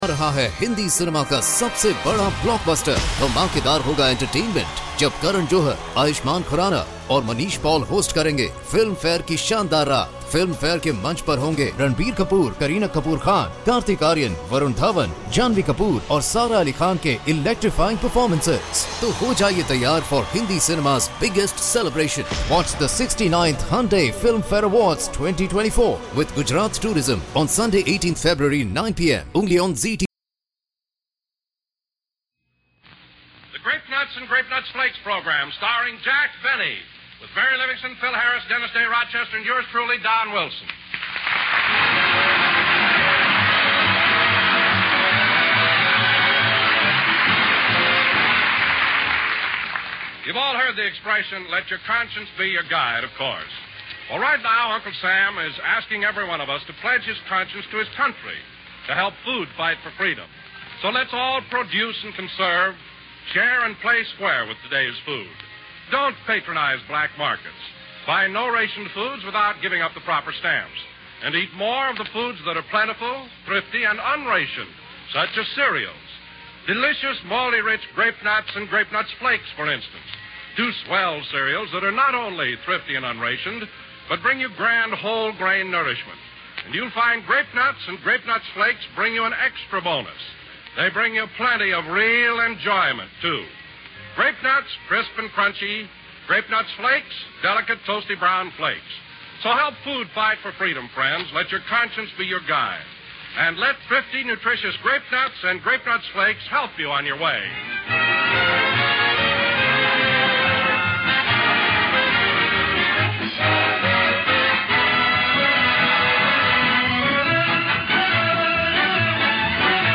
OTR Radio Christmas Shows Comedy - Drama - Variety.